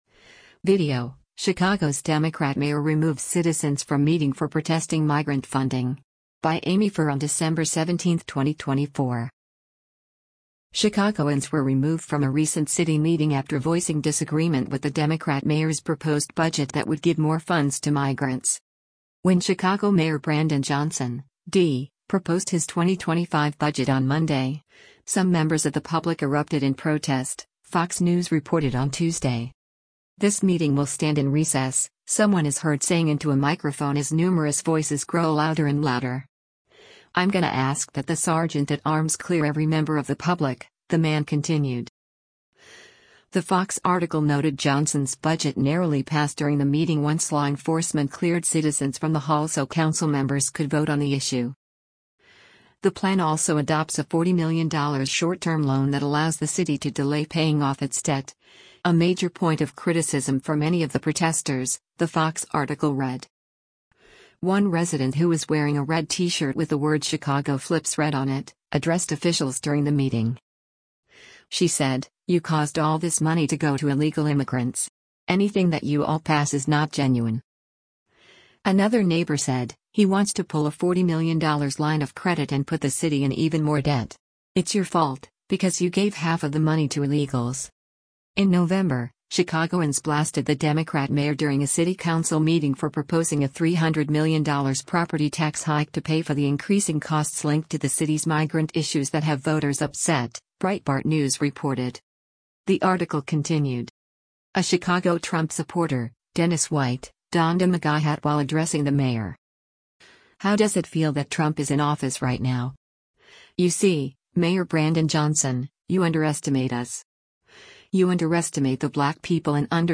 When Chicago Mayor Brandon Johnson (D) proposed his 2025 budget on Monday, some members of the public erupted in protest, Fox News reported on Tuesday.
“This meeting will stand in recess,” someone is heard saying into a microphone as numerous voices grow louder and louder.